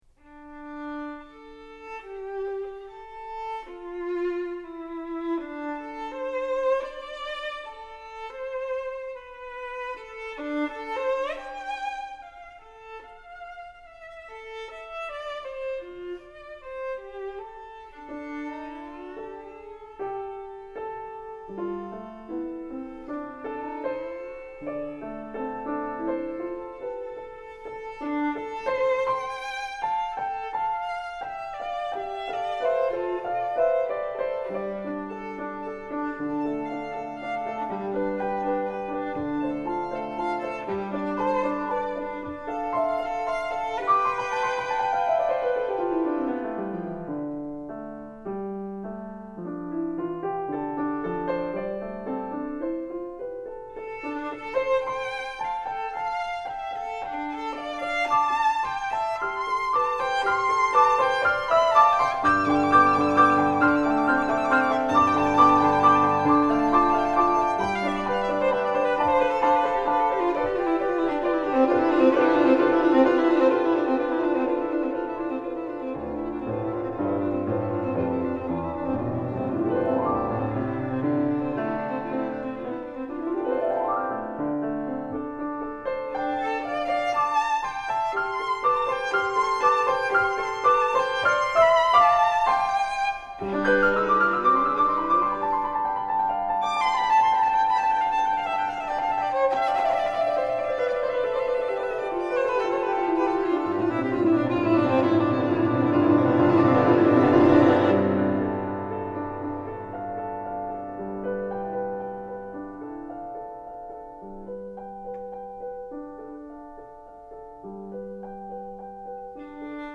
for Violin and Piano (1992)